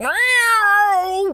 pgs/Assets/Audio/Animal_Impersonations/cat_scream_07.wav at master
cat_scream_07.wav